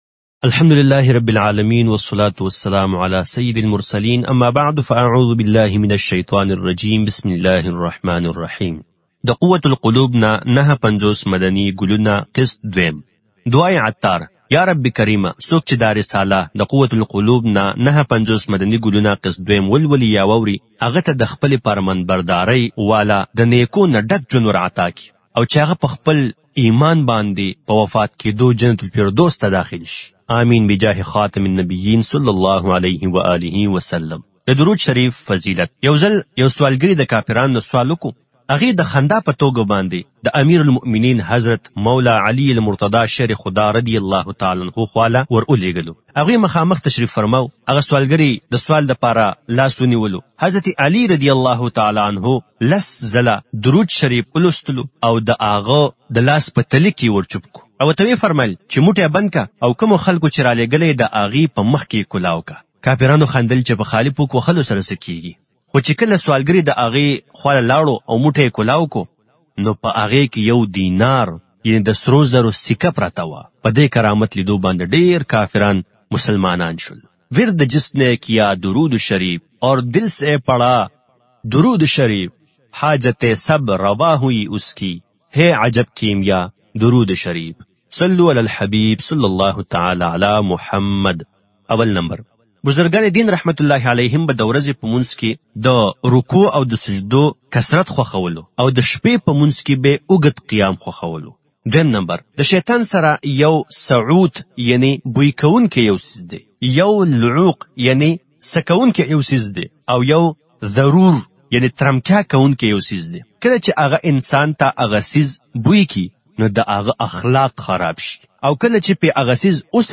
Audiobook - Qut Al Qulub se 59 Madani Phool Qist 2 (Pashto)